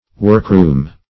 Workroom \Work"room`\, n.